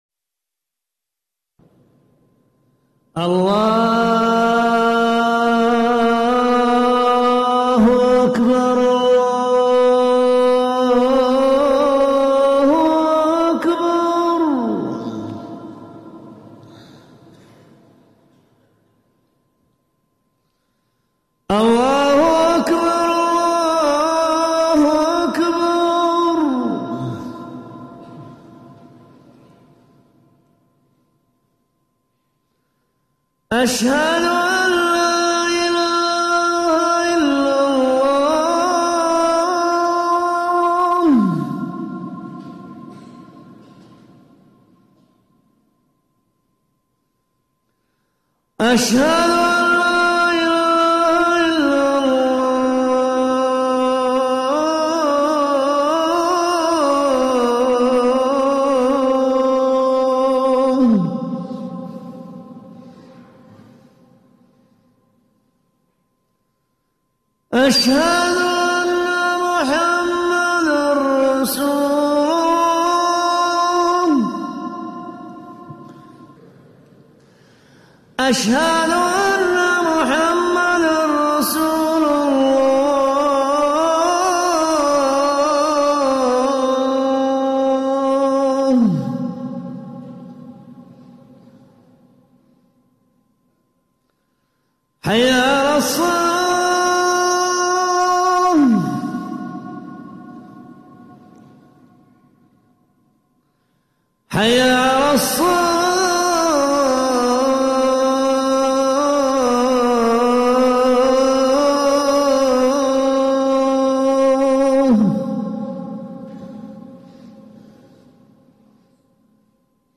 المكان: المسجد النبوي الشيخ
أذان